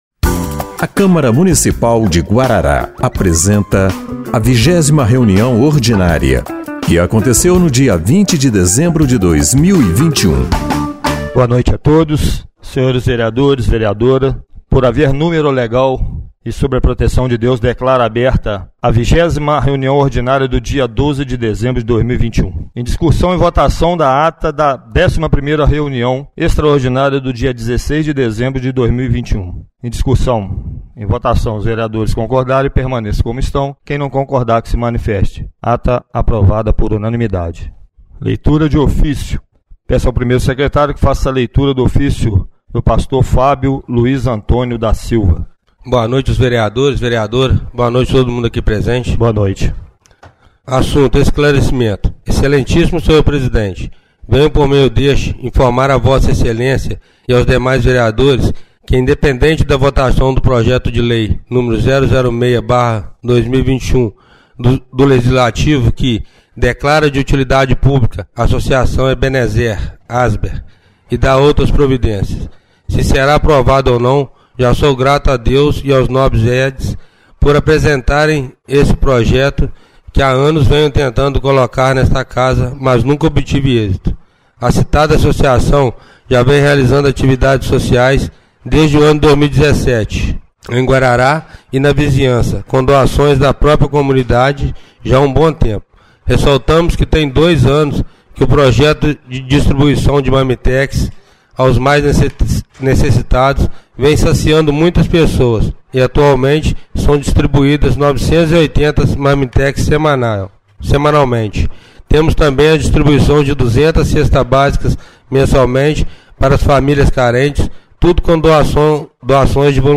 20ª Reunião Ordinária de 20/12/2021